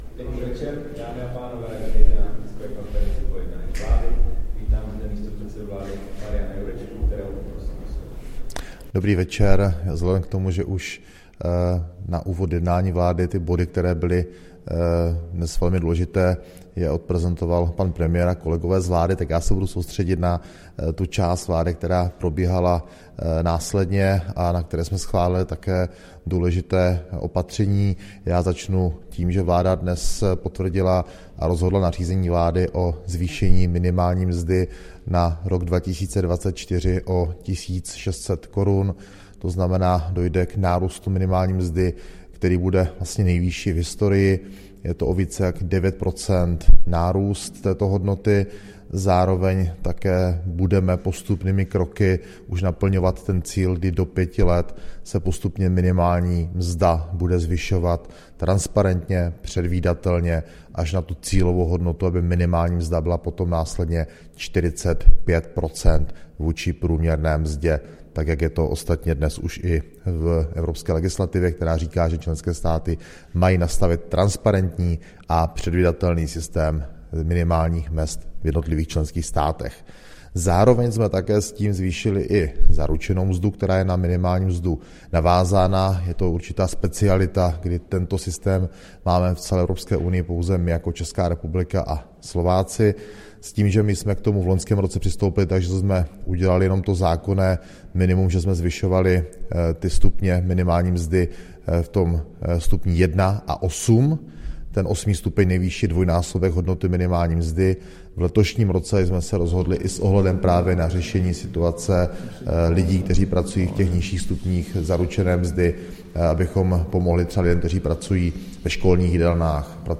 Tisková konference po jednání vlády, 13. prosince 2023